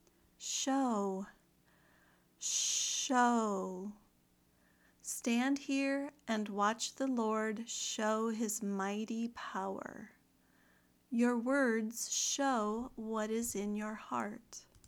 /ʃəʊ(verb)